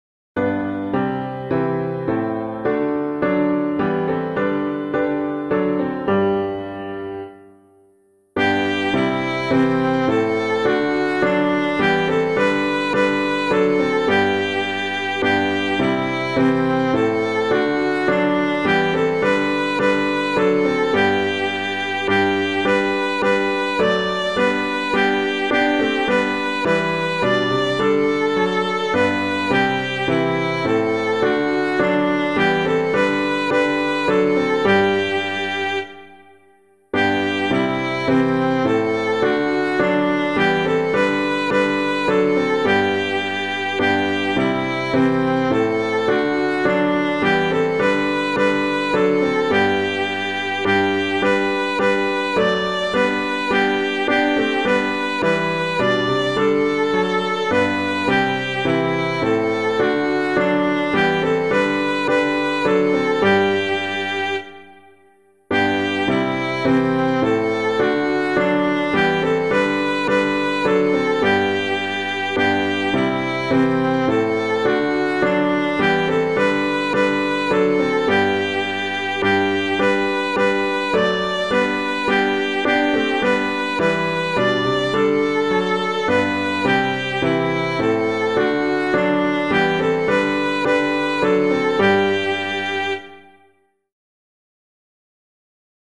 piano piano